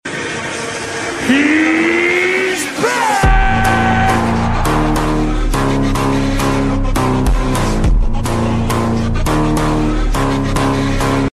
AI conversion Elon Musk The sound effects free download